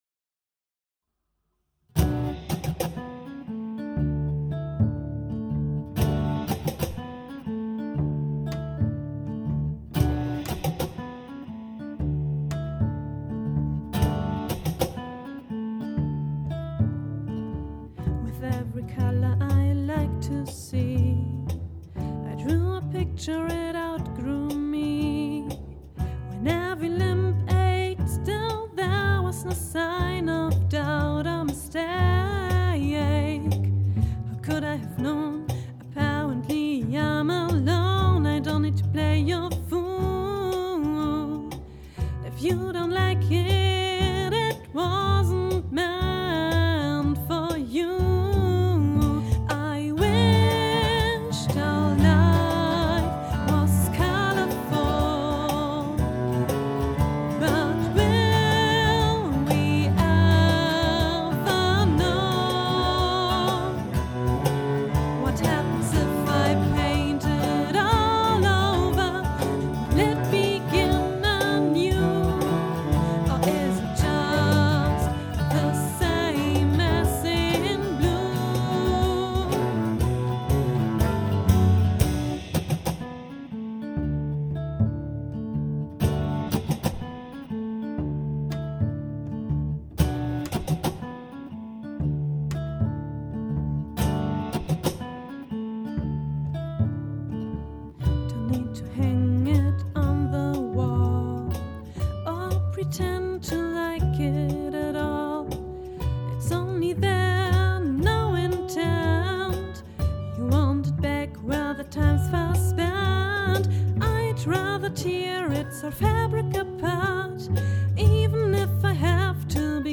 Gitarre & Gesang